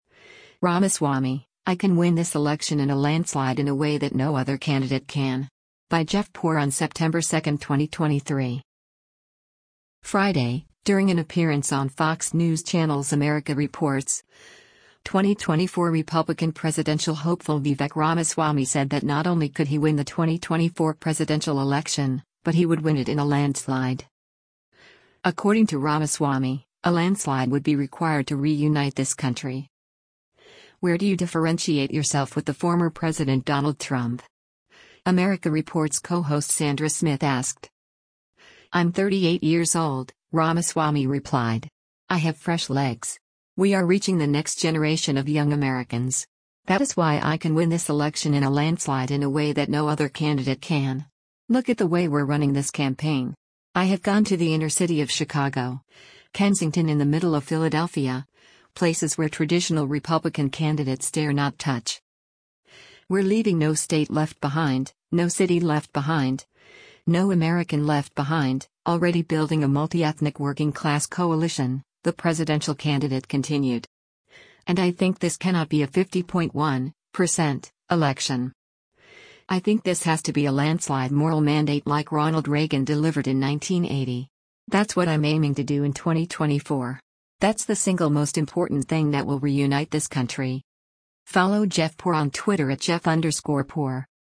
Friday, during an appearance on Fox News Channel’s “America Reports,” 2024 Republican presidential hopeful Vivek Ramaswamy said that not only could he win the 2024 presidential election, but he would win it in a “landslide.”
“Where do you differentiate yourself with the former President Donald Trump?” “America Reports” co-host Sandra Smith asked.